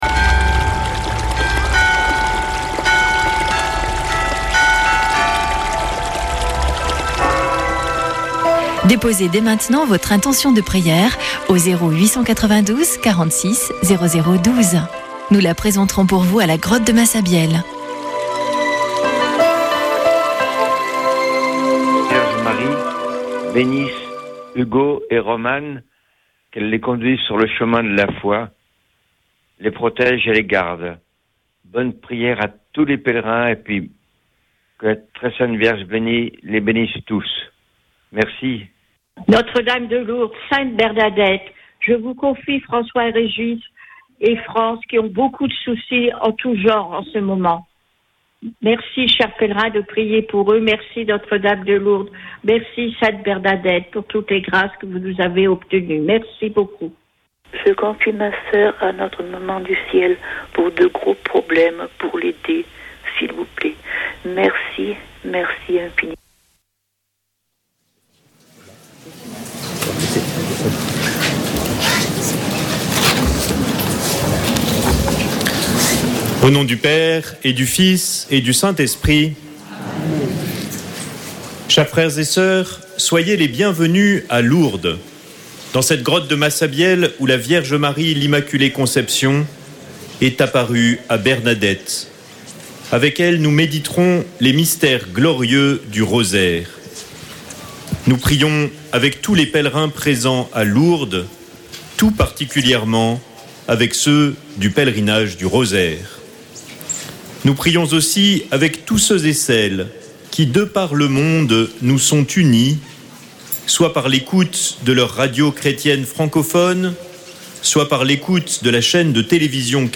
Une émission présentée par Chapelains de Lourdes